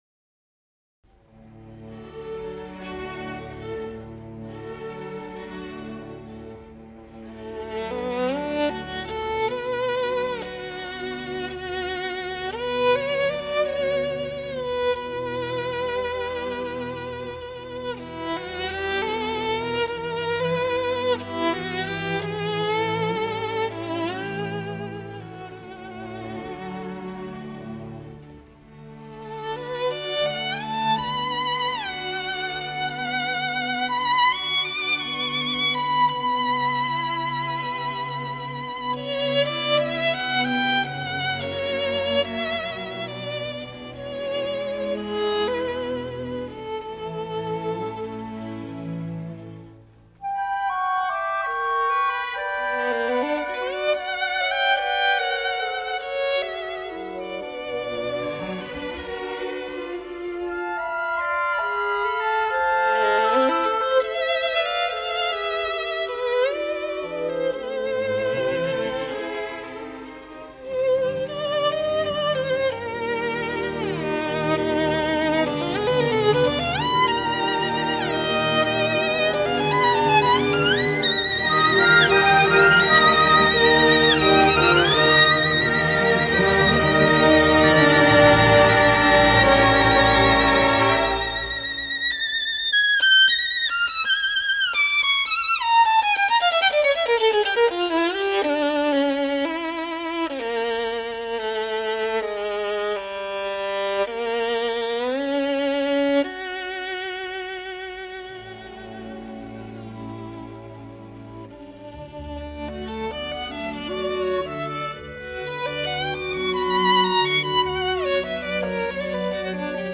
скрипка
симфонічний  оркестр